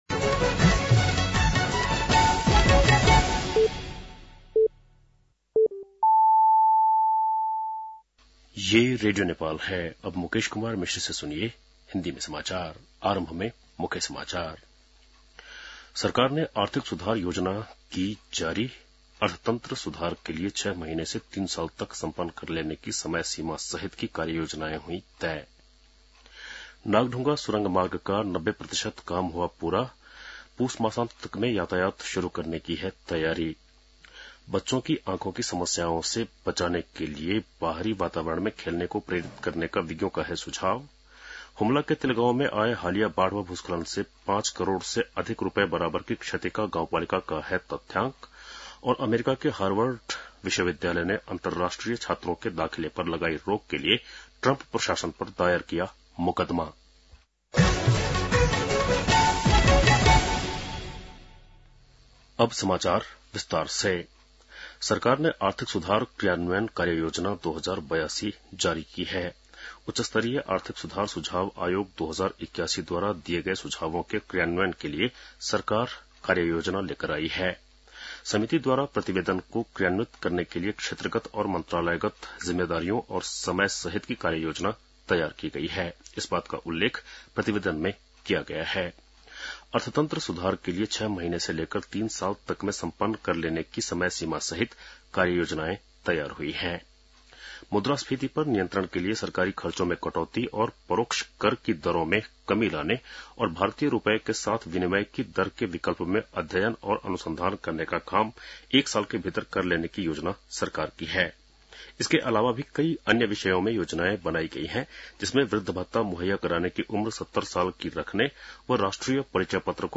बेलुकी १० बजेको हिन्दी समाचार : ९ जेठ , २०८२
10.-pm-hindi-news.mp3